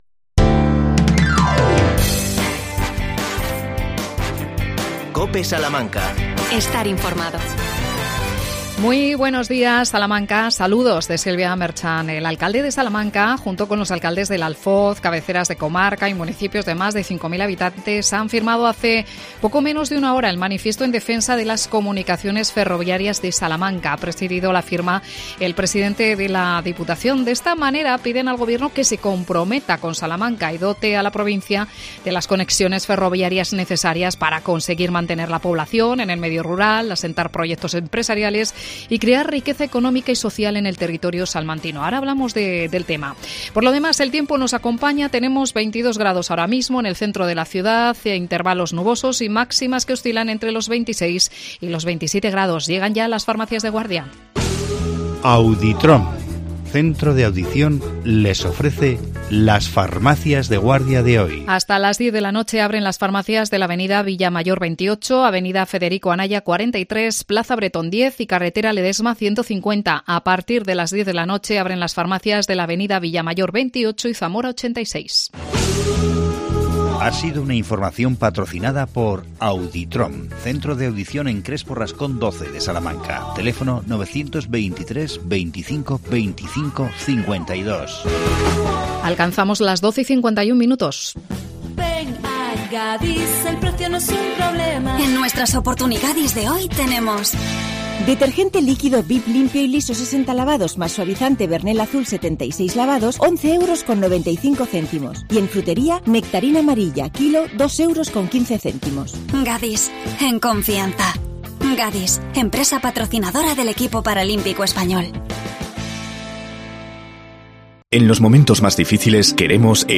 AUDIO: Entrevista a Isidoro Alanís, alcalde de Fuentes de Oñoro. El tema: las comunicaciones ferroviarias con Salamanca.